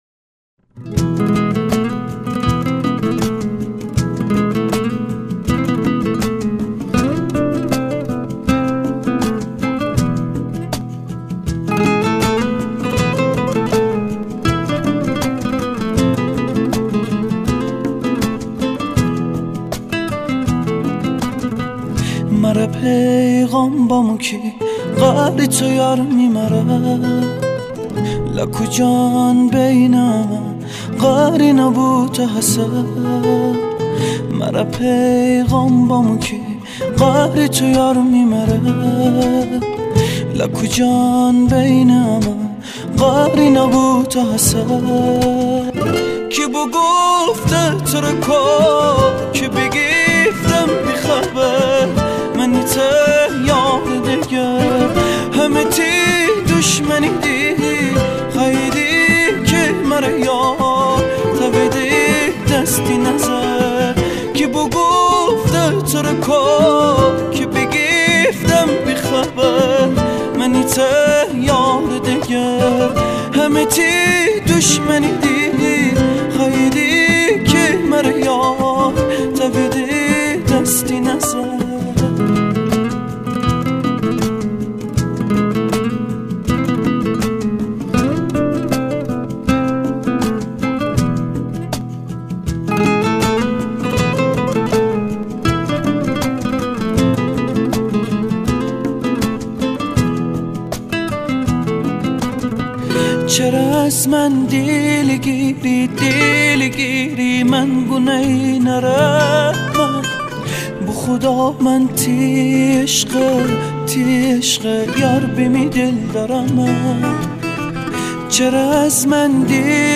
آهنگ محلی غمگین رشتی
Sad Local Song of Rashti